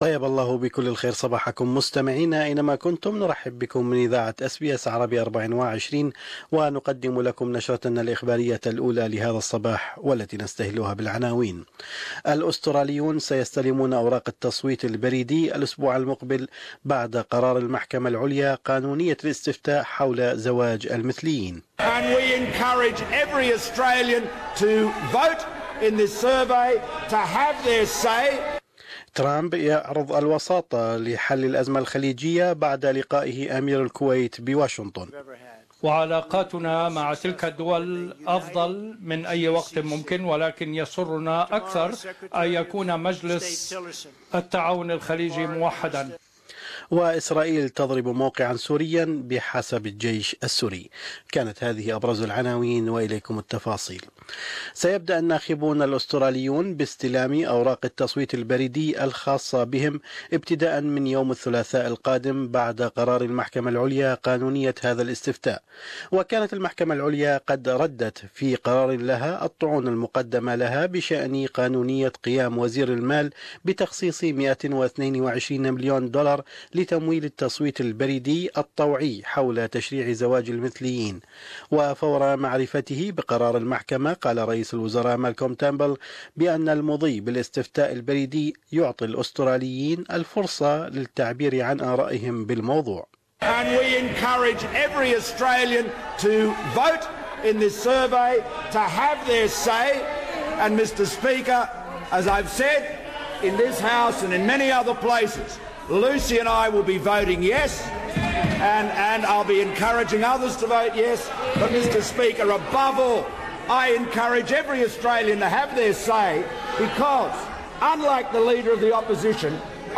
News Bulletin: Australian voters are to receive their postal ballots next Tuesday